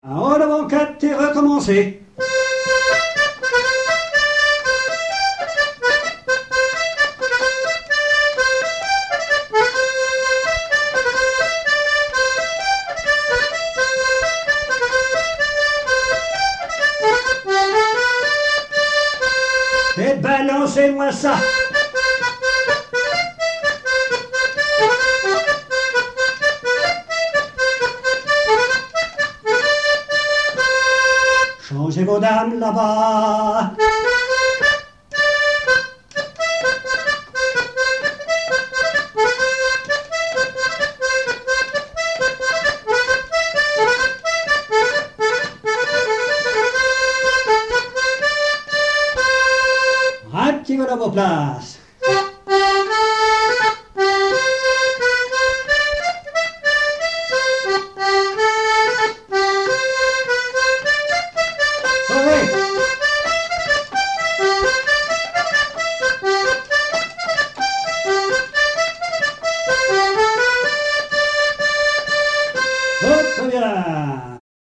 danse : quadrille : chaîne des dames
Pièce musicale inédite